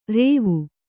♪ rēvu